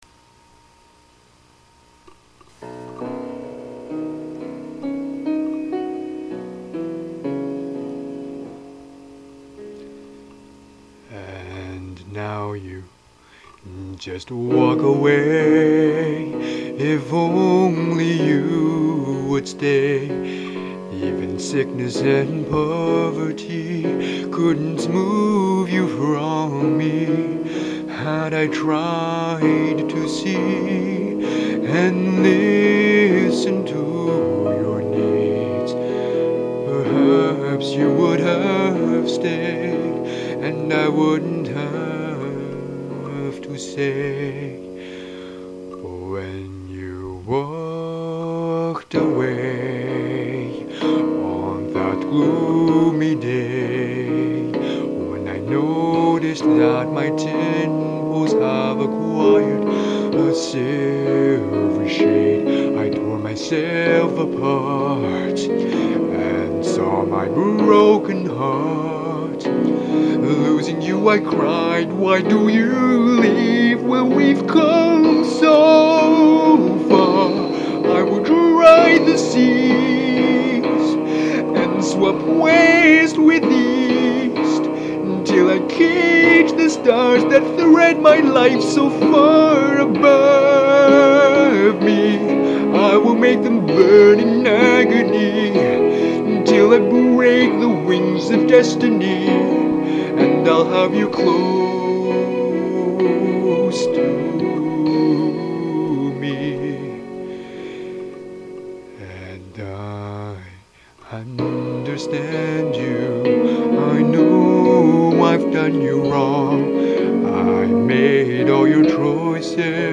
Italian Songs